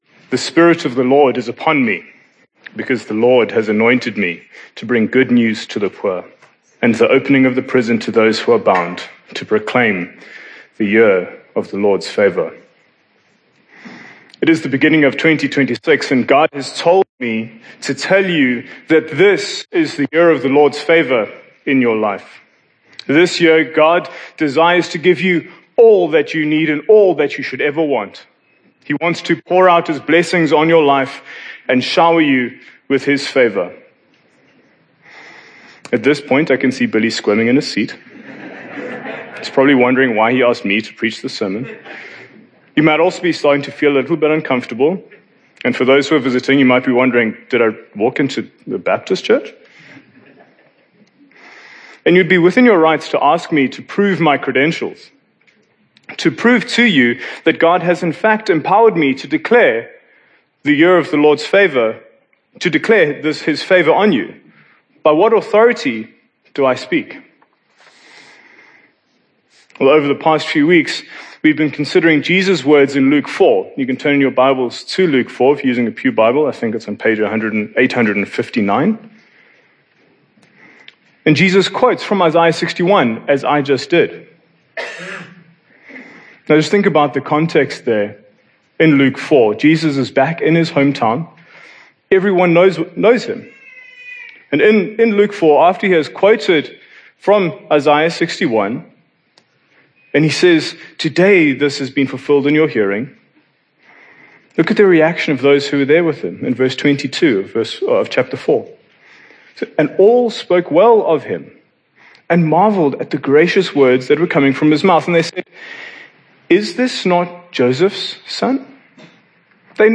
Sermons from Hillcrest Baptist Church, Durban South Africa